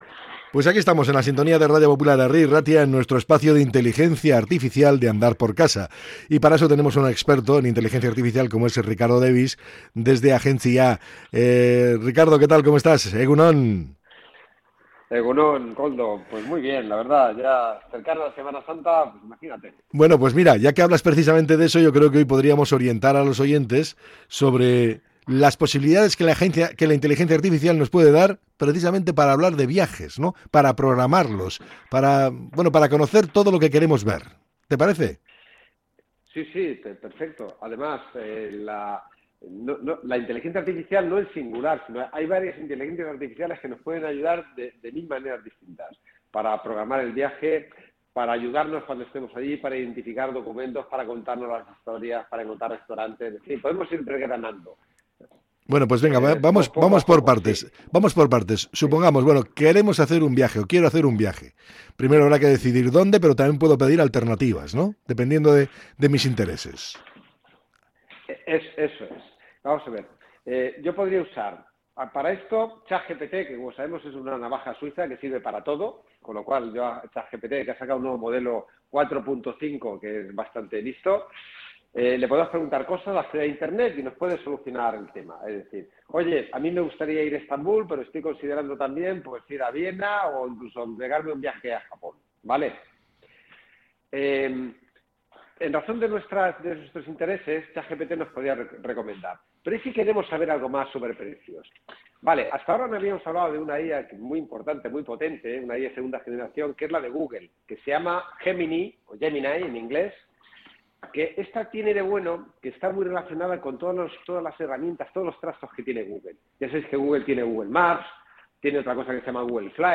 «Incluso si no vas a viajar, puede servirte como experiencia virtual. Es impresionante», ha comentado el experto entre risas.